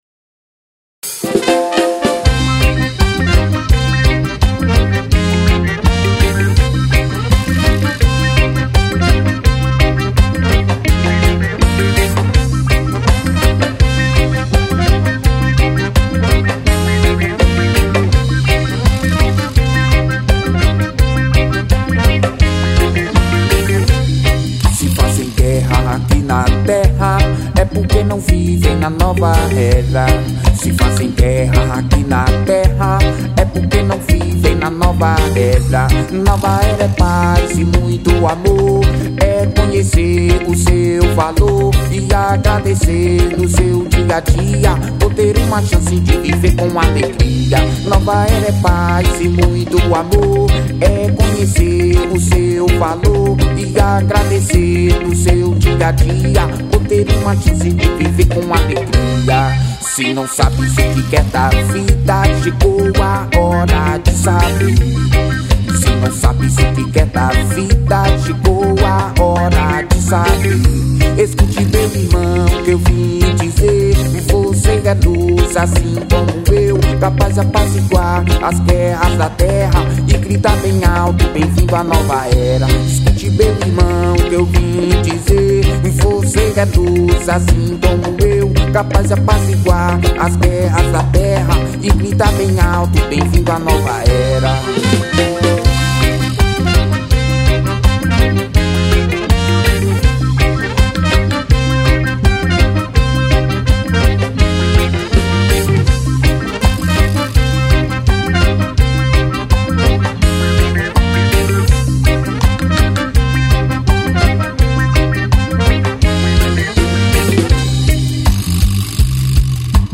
04:51:00   Reggae